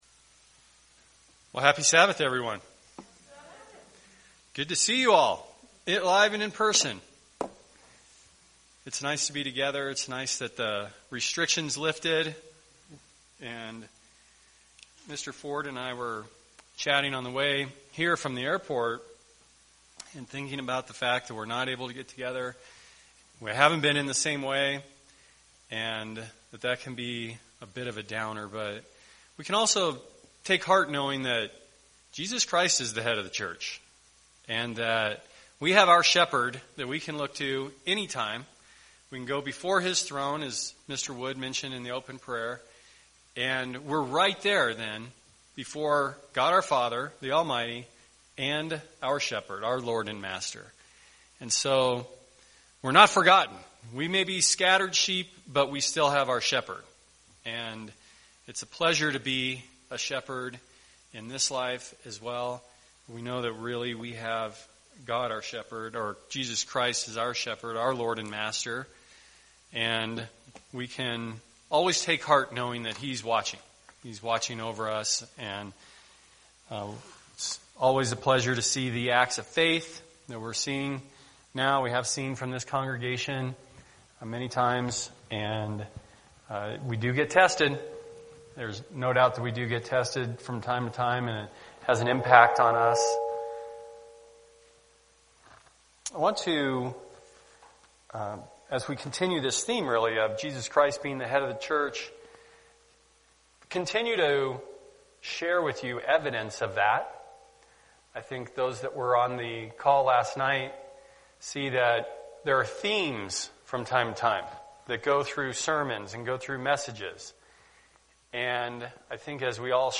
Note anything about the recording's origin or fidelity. Given in Albuquerque, NM